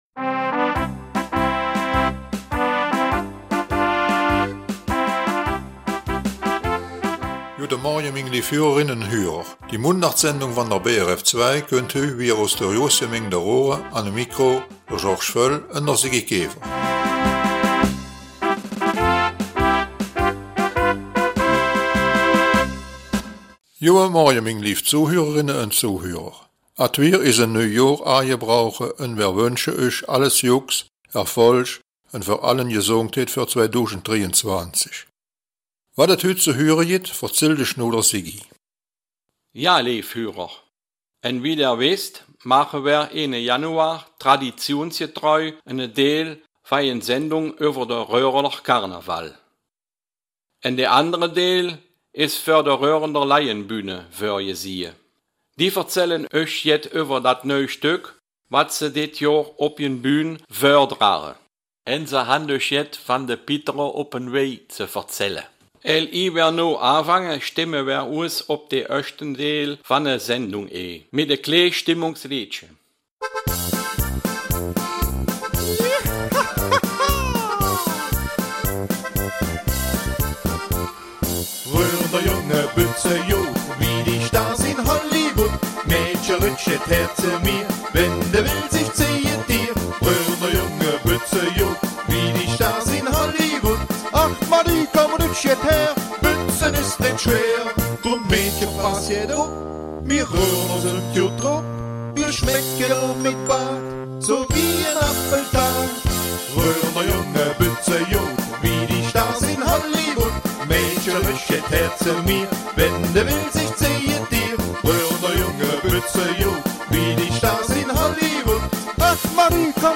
Den zweiten Teil der Sendung übernimmt traditionsgemäß die Raerener Leihenbühne die ihr neues Theaterstück ''Brassel e-jen Köjsche'' vorstellt und sie hat ein Interview mit ''de Pittere op jen Weij'' gemacht.